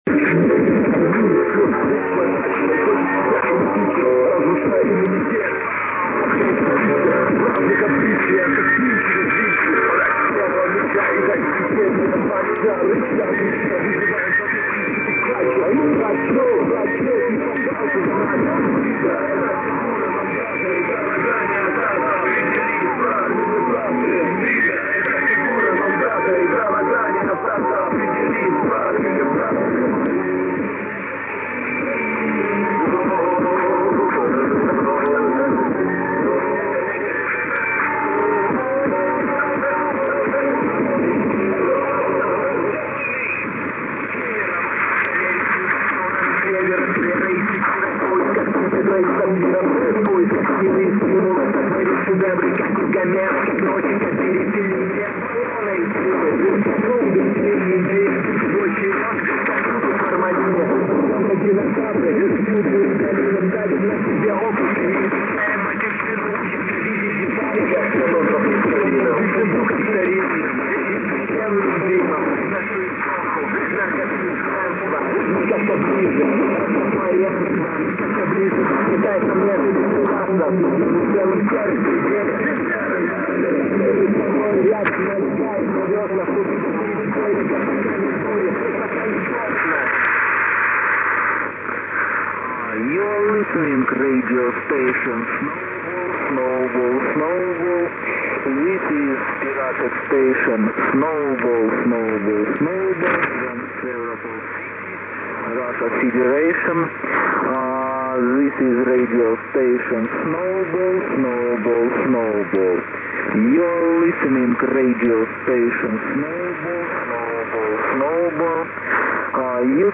Russian rap-music and announcements in English on 6630 kHz!
mp3 - Good signal, O=3